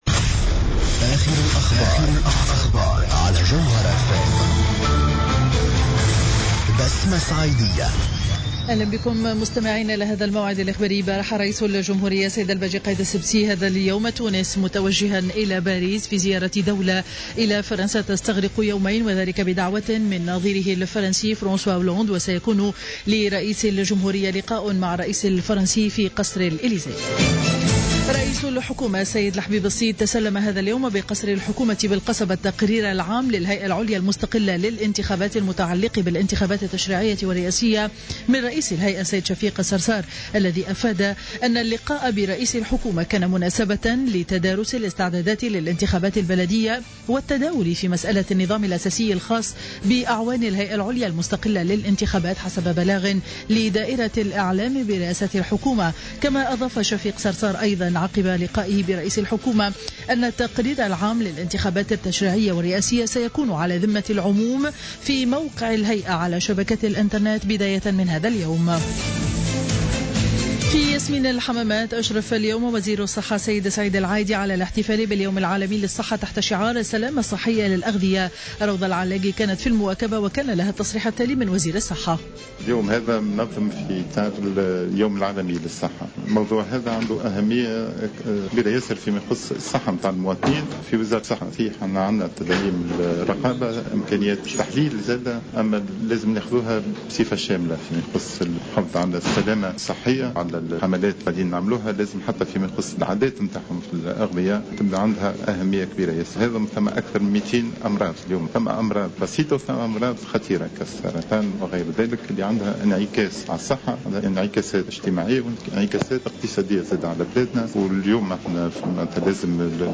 نشرة أخبار منتصف النهار ليوم الثلاثاء 7 أفريل 2015